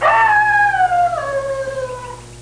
1 channel
wolf1.mp3